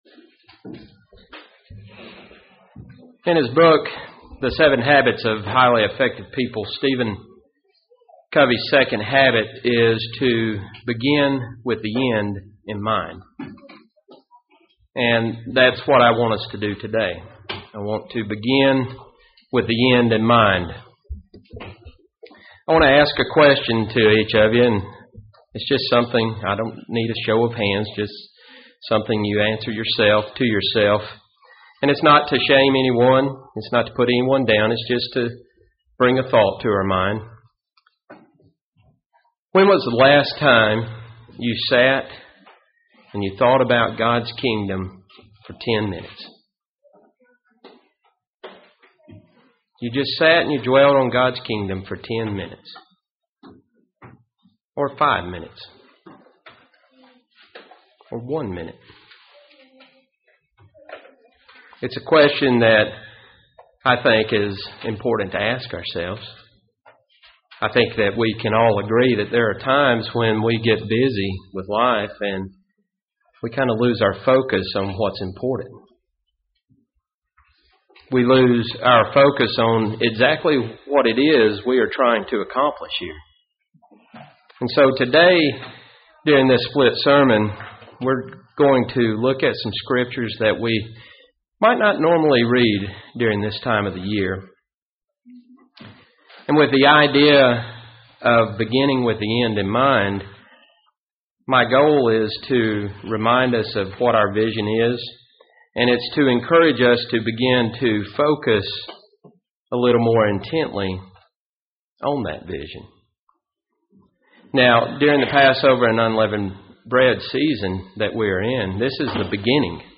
Given in Gadsden, AL Huntsville, AL